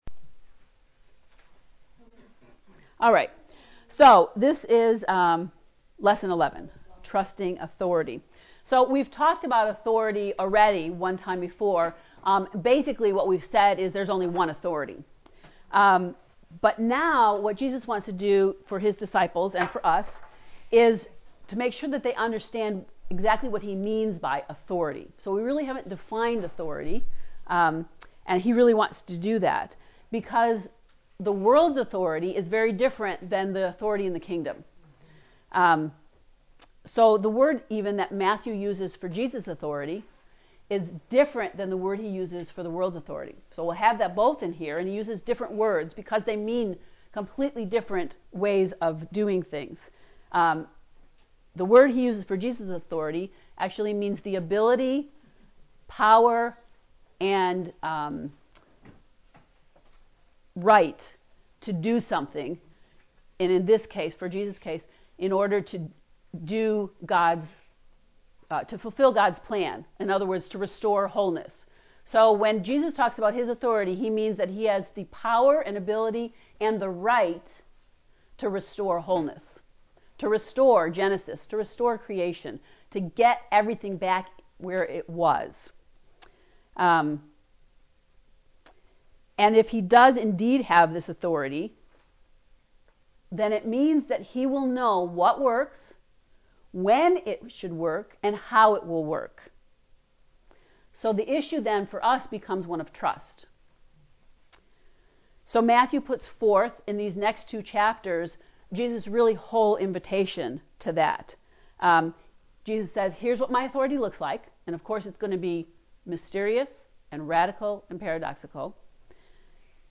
To listen to the lecture on lesson 11 “Trusting Authority” click below: